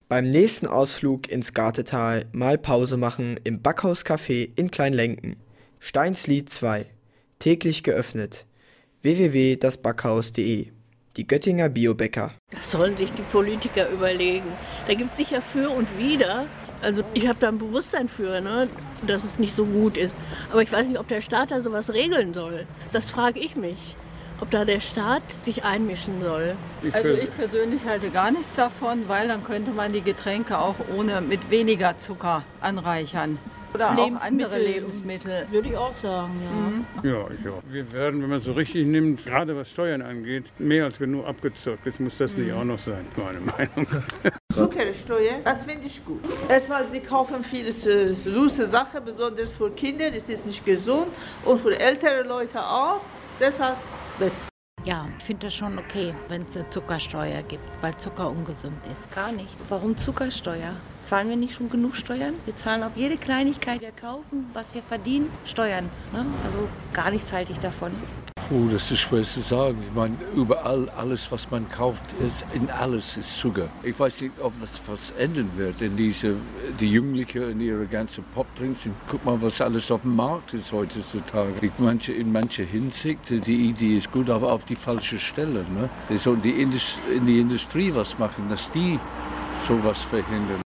Aber was halten die Göttinger von einer Einführung der Zuckersteuer?